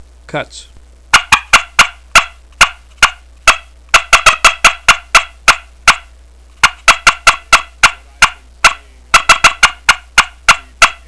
Listen to 11 seconds of cutts
ccpushpincutts11.wav